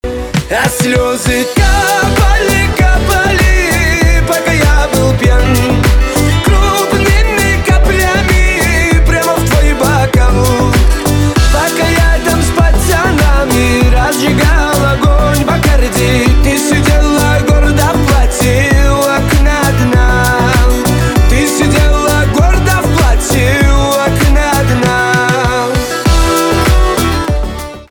кавказские
грустные , печальные
гитара , битовые , басы